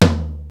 Index of dough-samples/ tidal-drum-machines/ machines/ AkaiLinn/ akailinn-lt/
Tom L.wav